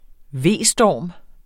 Udtale [ ˈveˀ- ]